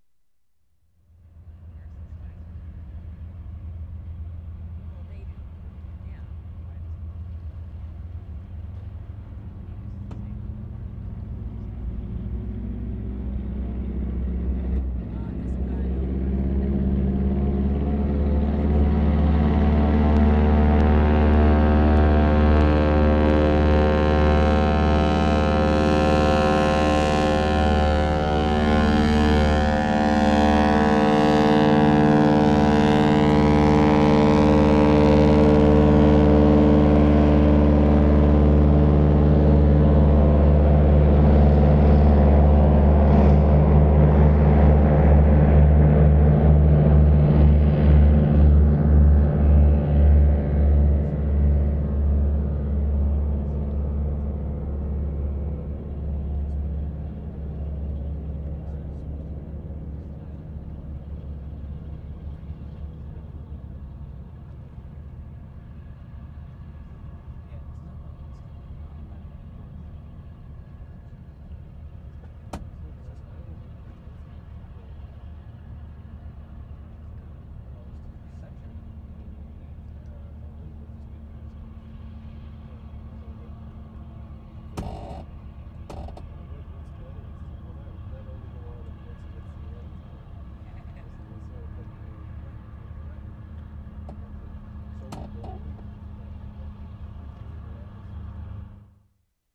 WATERFRONT / CP WHARF Sept. 13, 1972
6. *0'10" a very loud seaplane taking off nearby, note the heavy low frequencies.